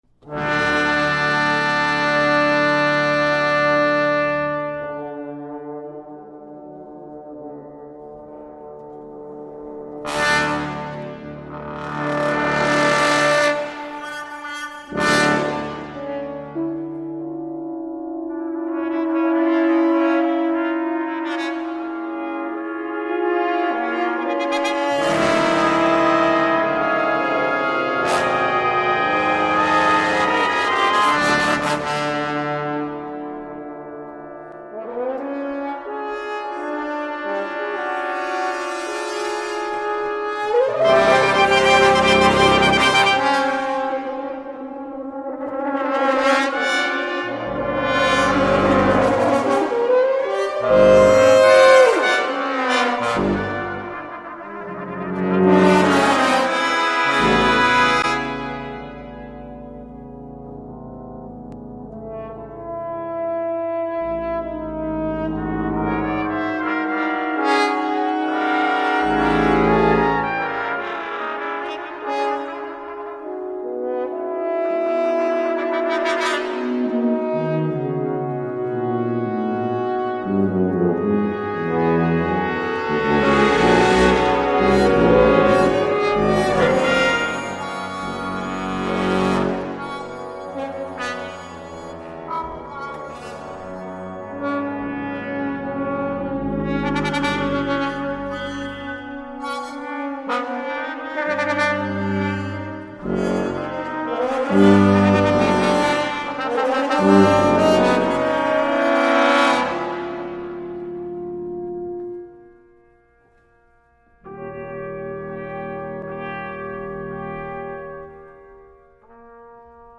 [3 horns, 4 tpts. 3 tbs. 1 tuba] (10′)